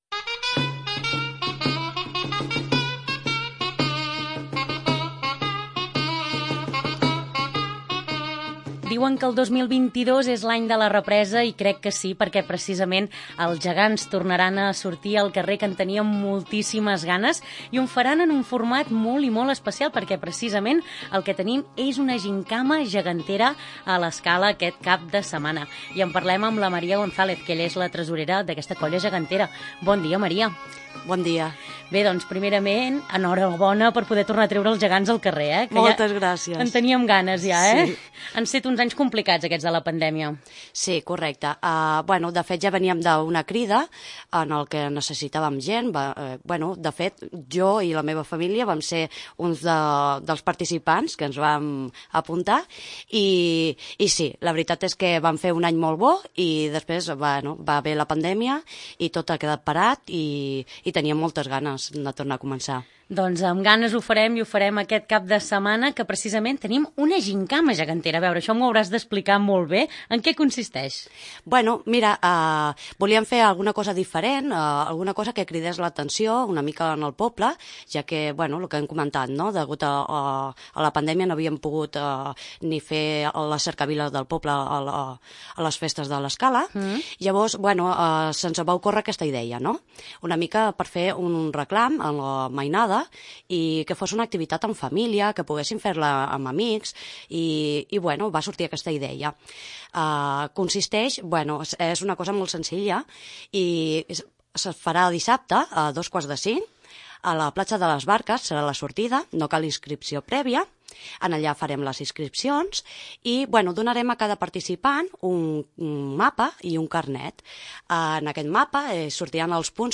Entrevista: Gimcana Gegantera de l'Escala